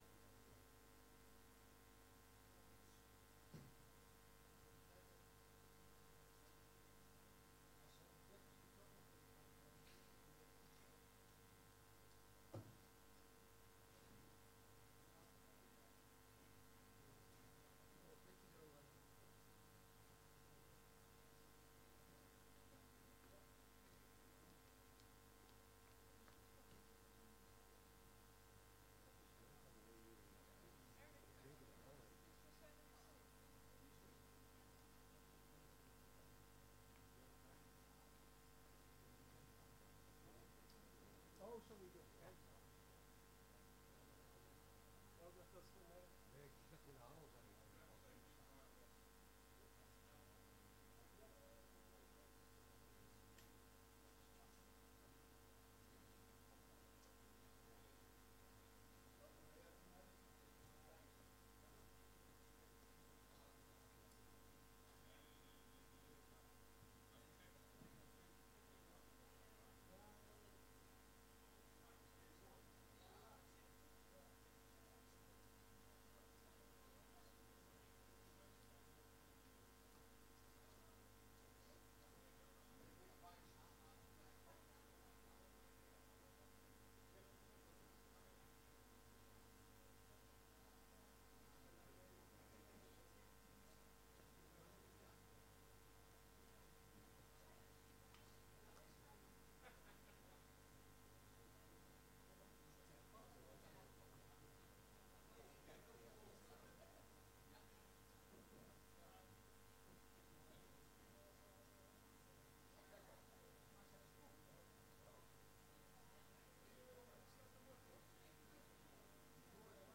Raadsvergadering 07 november 2019 18:30:00, Gemeente Dronten
Locatie: Raadzaal